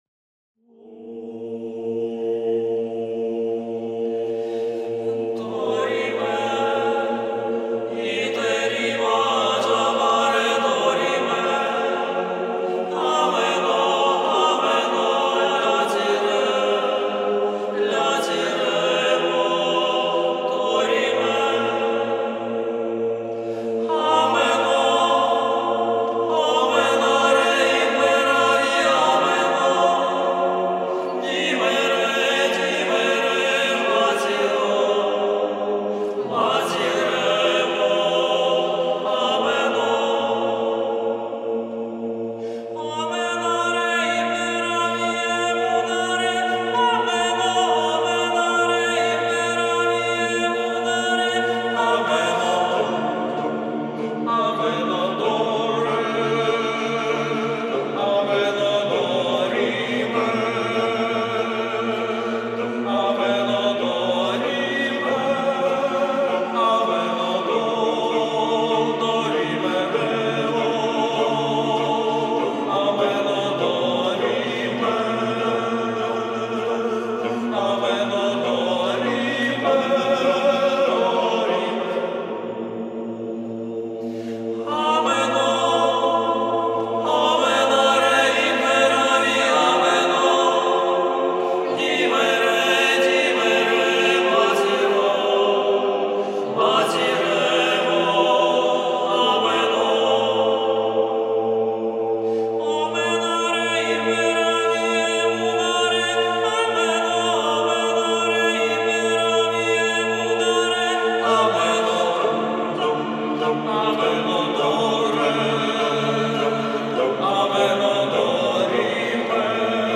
Gemäß der gregorianischen Tradition singen sie einstimmig.